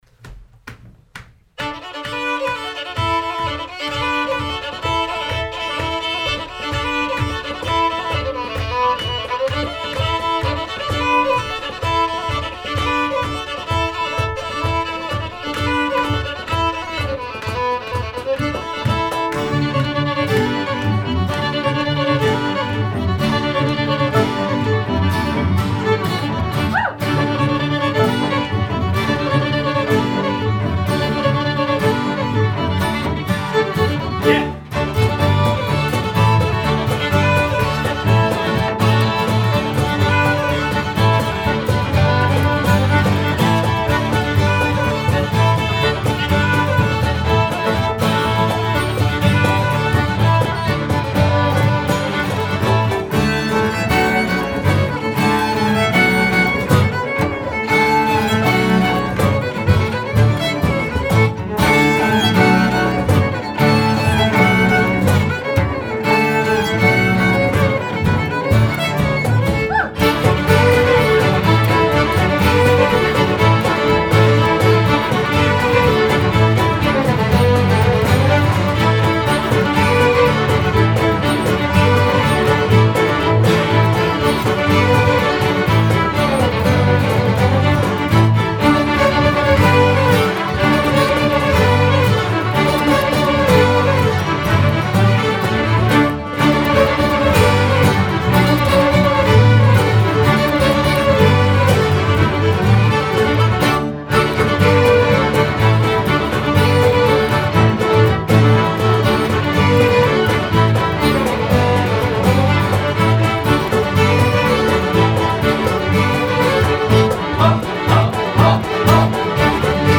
Download Music from A Live Performance